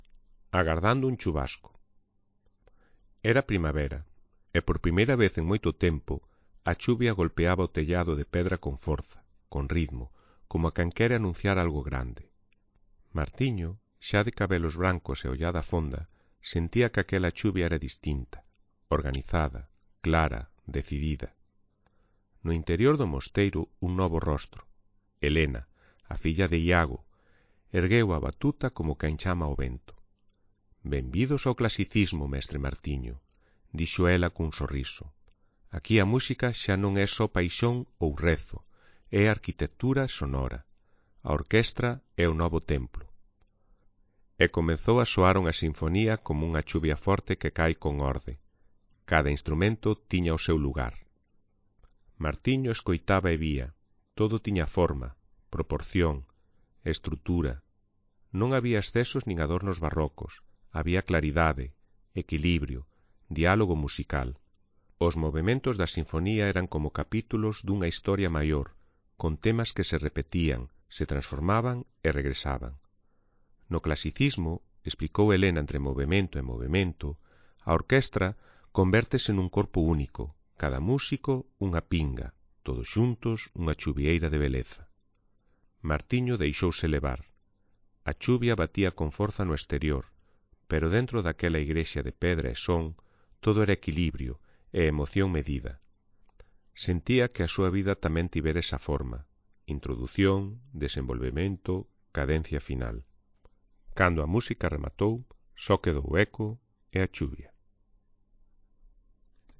Agardando_un_chuvasco_-_baixa_calidade.mp3